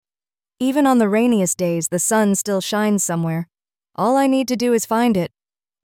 🎤 Emotional Quote TTS 🎵 Background Music